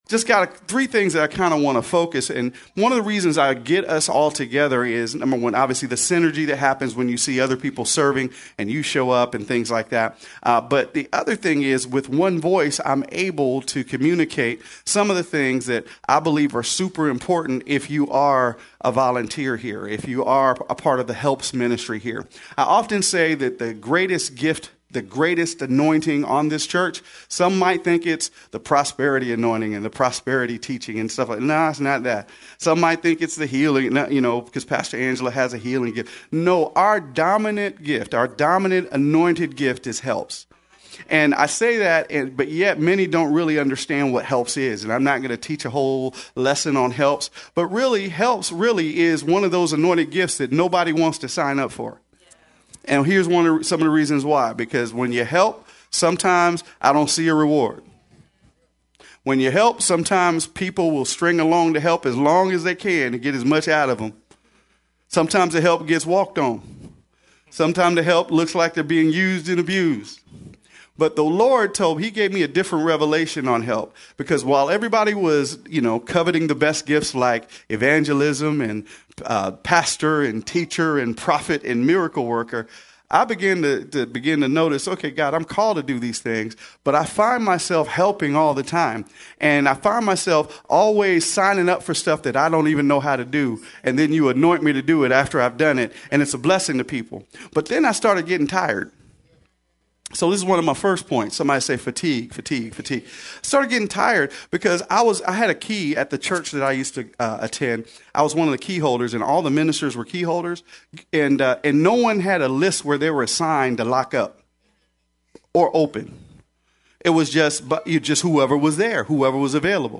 20:58 Volunteer Rally March 2019 SERVING WITH EXCELLENCE -Taught during our March 2019 Volunteer Rally- In this teaching